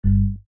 button_click.mp3